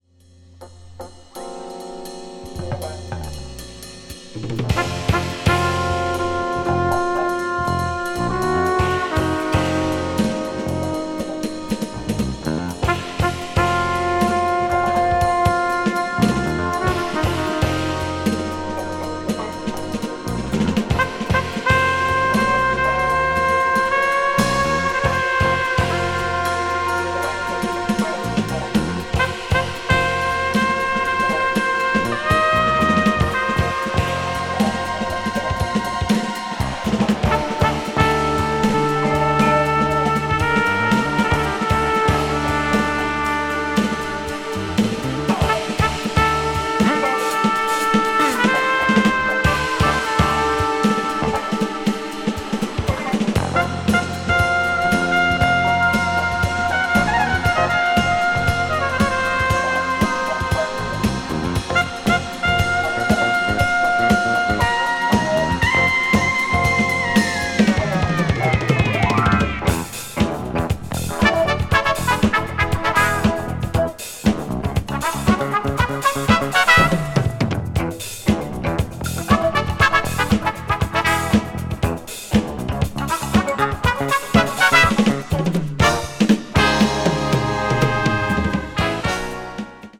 brazilian fusion   crossover   jazz groove   jazz rock